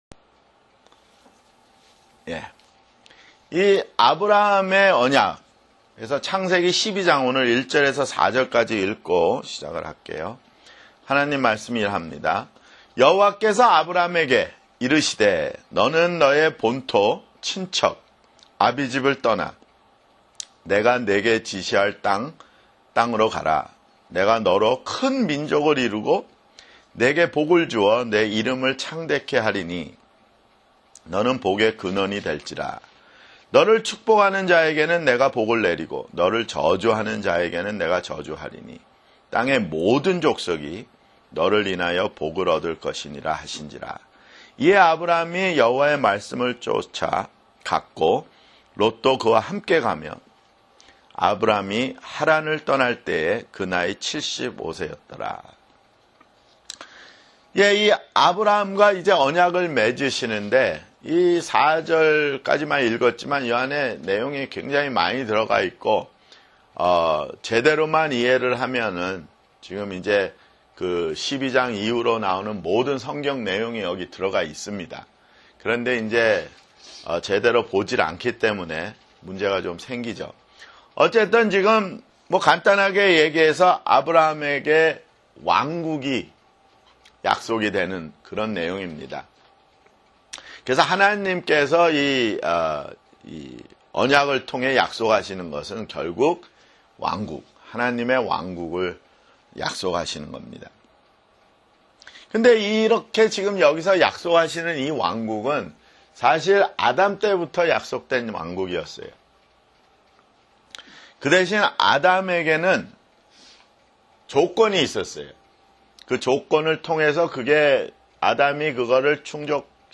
[성경공부] 창세기 (47)